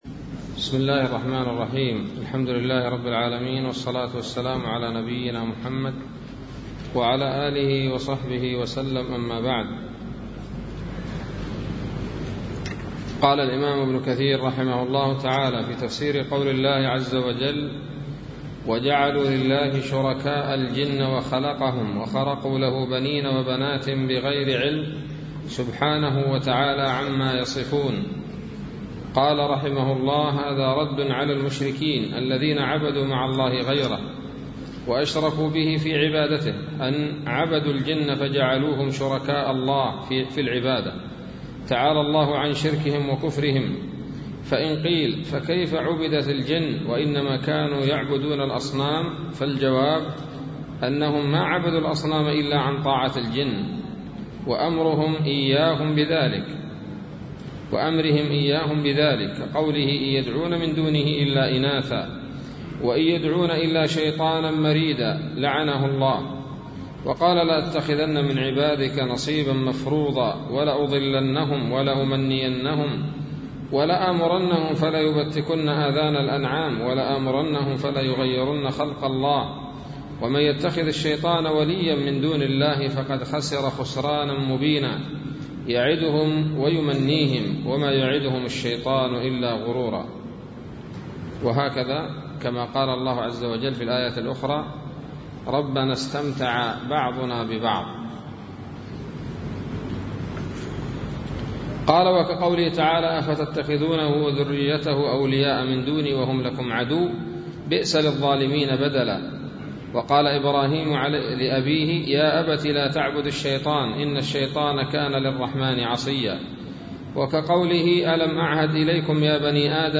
الدرس الخامس والثلاثون من سورة الأنعام من تفسير ابن كثير رحمه الله تعالى